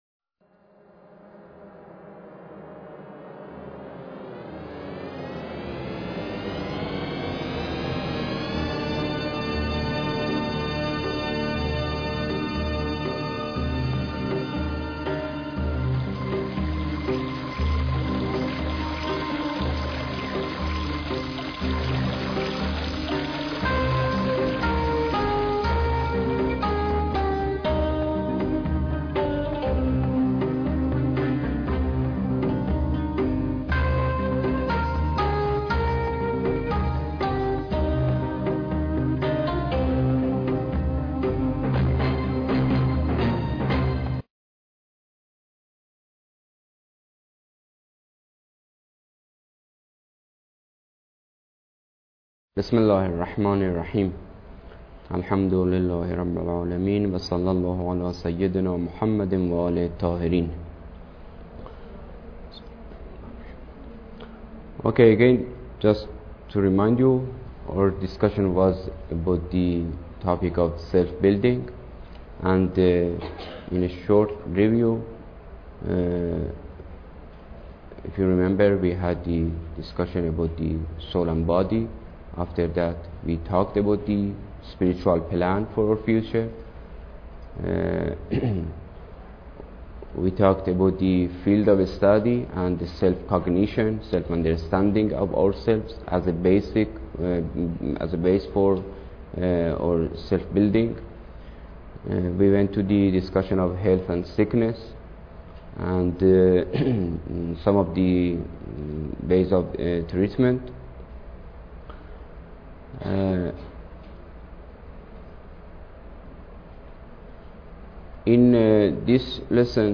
Lecture_9